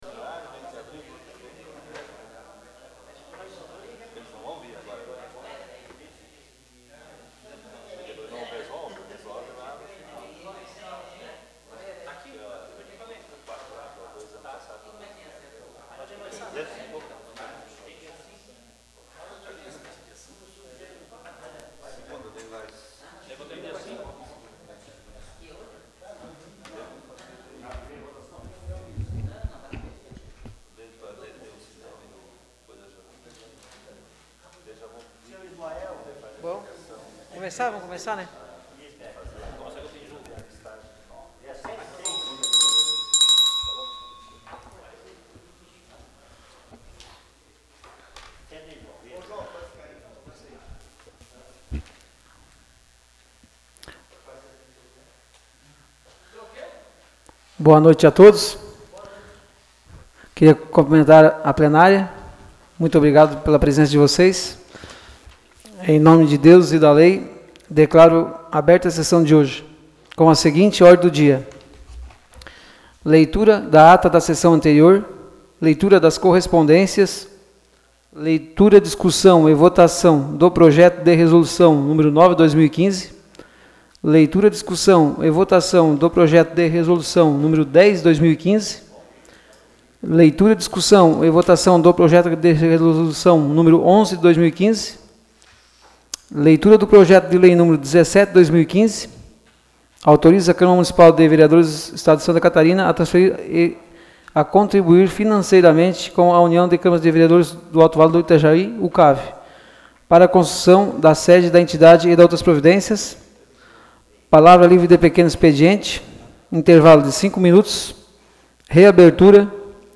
Áudio da Sessão Ordinária realizada em 28 de setembro de 2015.